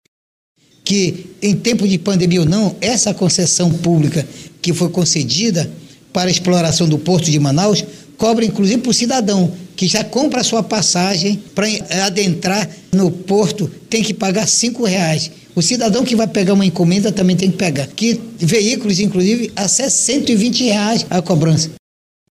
A denúncia de abuso na cobrança ocorreu durante sessão plenária nessa semana.
Sonora-1-Sinesio-Campos-–-deputado-estadual.mp3